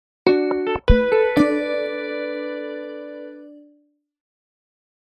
Sello sonoro